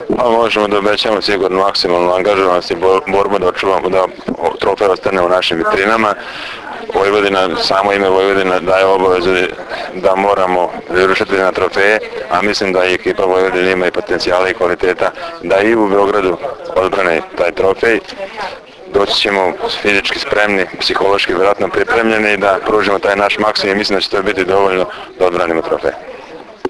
U pres sali SD Crvena zvezda danas je održana konferencija za novinare povodom Finalnog turnira 46. Kupa Srbije u konkurenciji odbojkaša.
IZJAVA